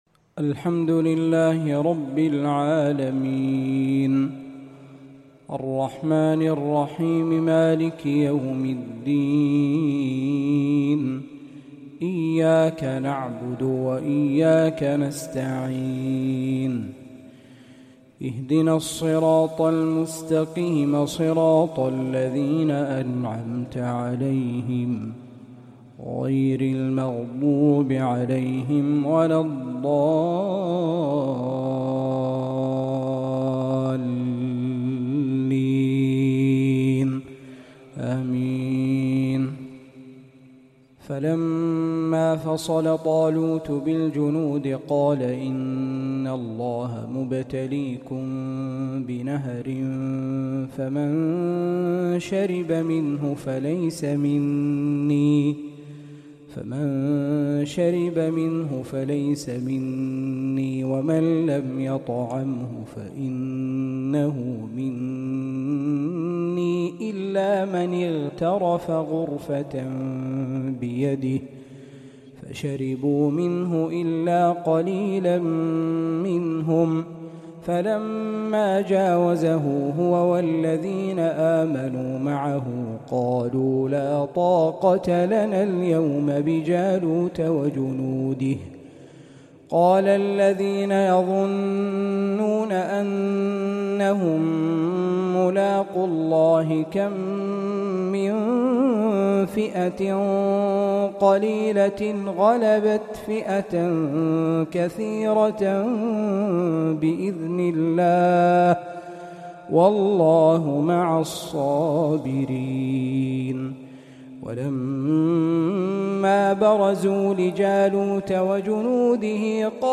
تحبير حجازي بديع من سورة البقرة